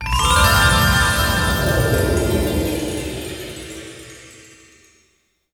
WARMNFUZZY.wav